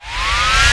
WindU2.ogg